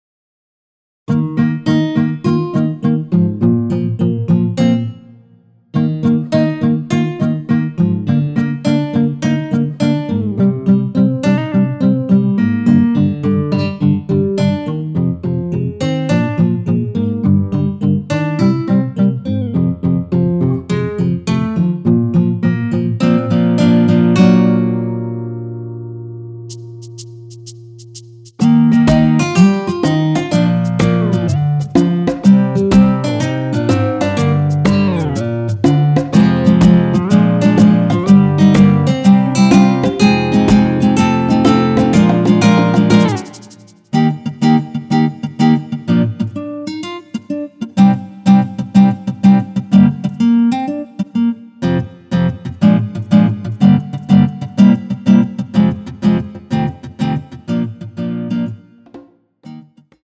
전주 없이 시작 하는곡이라 노래 하시기 편하게 전주 2마디 많들어 놓았습니다.(미리듣기 확인)
원키에서(-1)내린 MR입니다.
Eb
앞부분30초, 뒷부분30초씩 편집해서 올려 드리고 있습니다.